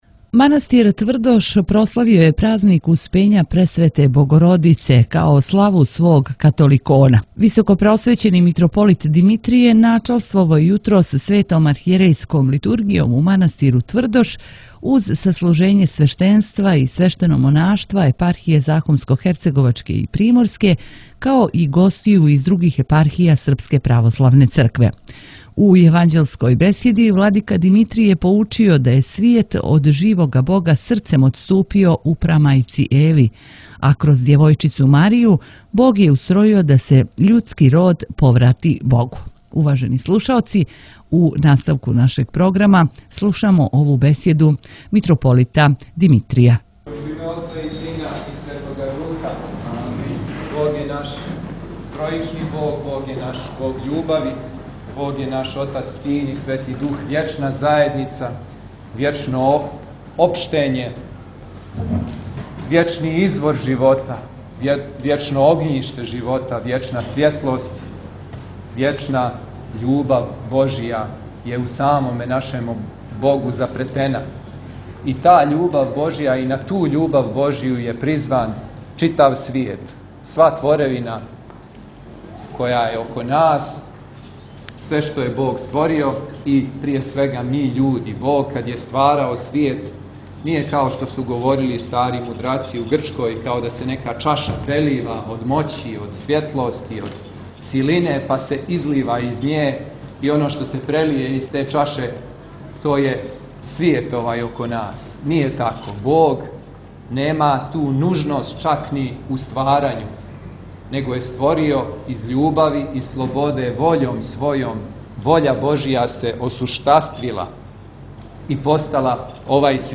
Поводом прославе манастирске славе, Успења Пресвете Богородице, Његово високопреосвештенство Архиепископ и Митрополит милешевски г. Атанасије служио је у среду 28. […]